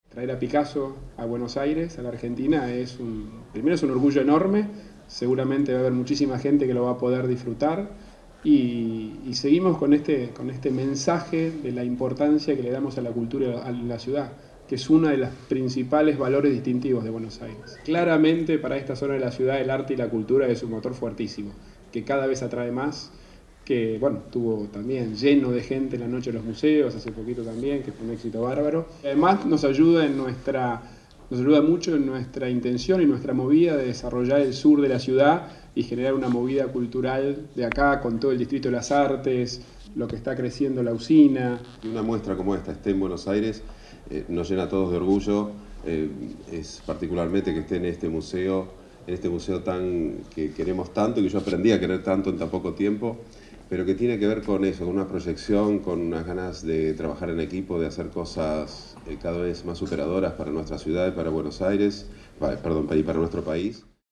El jefe de Gobierno de la Ciudad de Buenos Aires, Horacio Rodríguez Larreta, presentó la exposición “Pablo Picasso. Más allá de la semejanza”, que se exhibirá desde el 18 de noviembre hasta el 28 de febrero en el Museo de Arte Moderno, y destacó que “traer a Picasso a Buenos Aires y la Argentina es un orgullo enorme”.